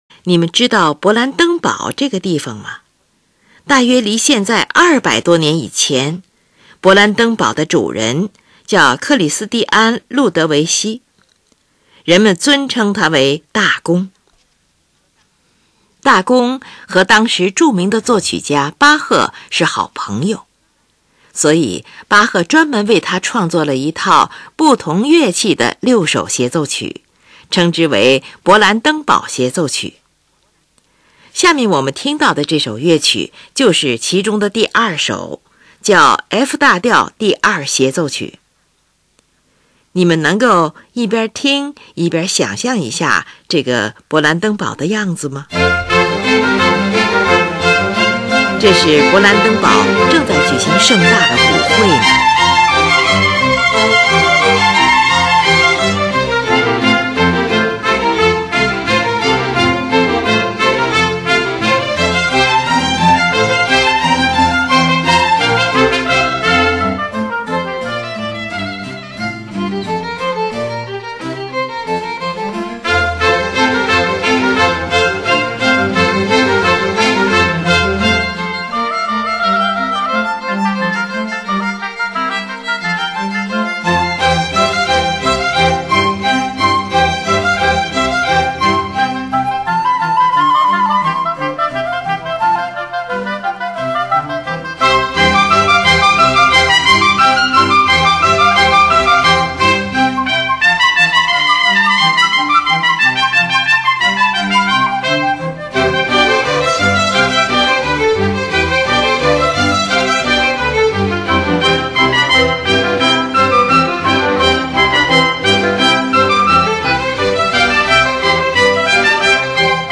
协奏曲，乐队协奏曲（大协奏曲），一般指为同类独奏乐器（如管乐器类）而写作的乐队作品。
第一乐章，快板，F大调，2/2拍子，呈现出兴高采烈的富于节奏感的主题